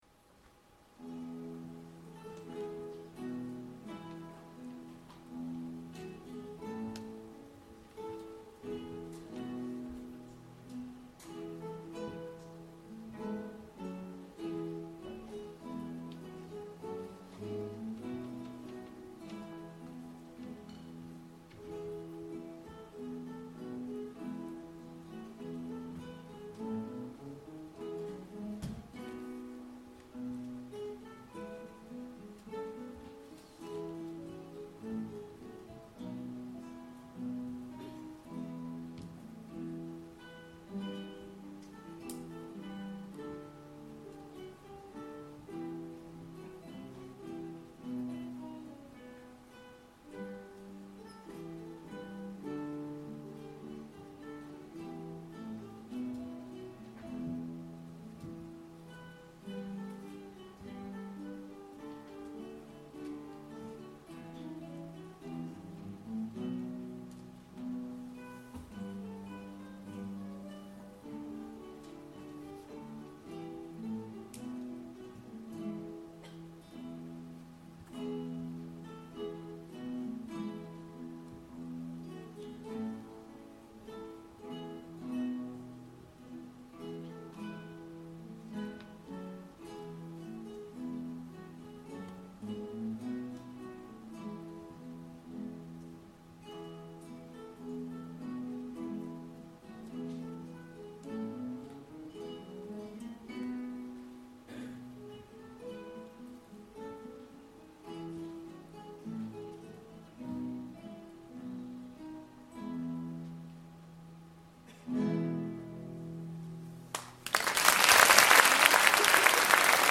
Guitar Ensemble
Performed at the Autumn Concert, November 2014 at the Broxbourne Civic Hall. Ballet Apache